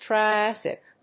発音記号
• / trɑɪˈæsɪk(米国英語)